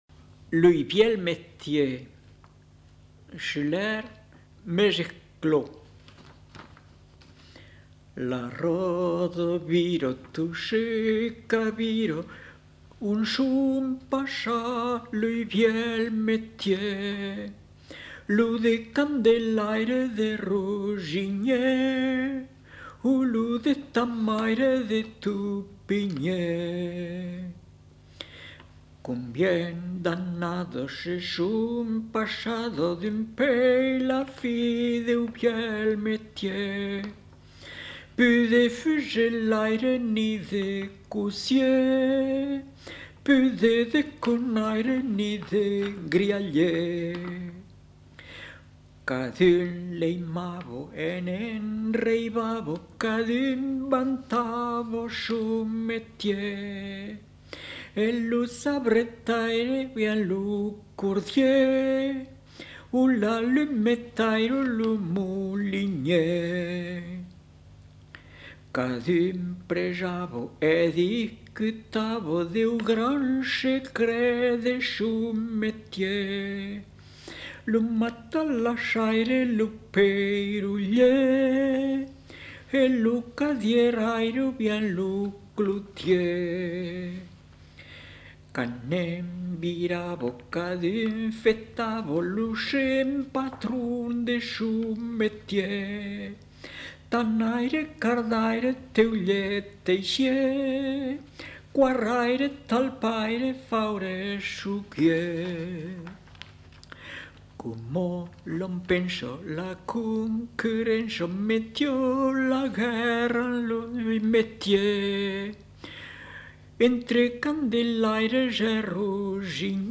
Aire culturelle : Périgord
Lieu : Lolme
Genre : chant
Effectif : 1
Type de voix : voix d'homme
Production du son : chanté